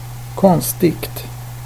Ääntäminen
IPA : ['streɪn.dʒ] US : IPA : ['streɪn.dʒ]